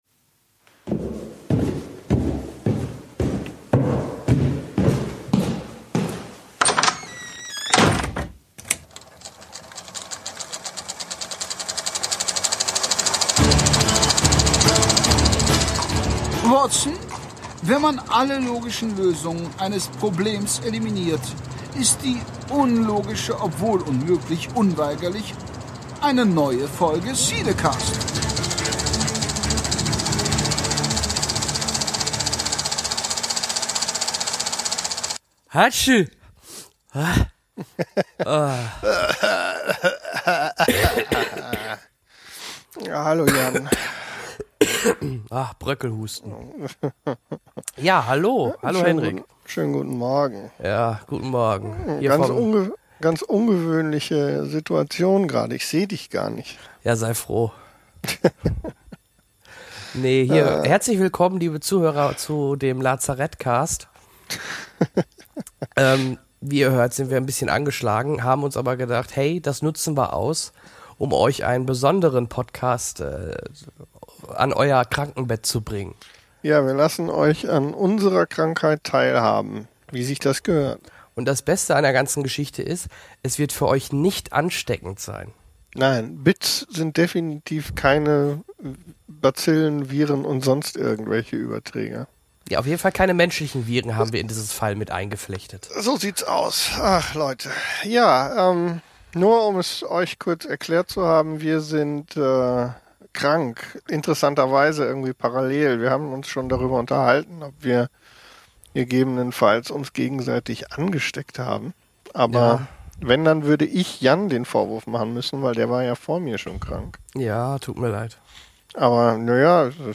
Was liegt da näher als sich ans Mikro zu schleichen und einen kleinen Krankencast aufzunehmen.